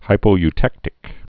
(hīpō-y-tĕktĭk)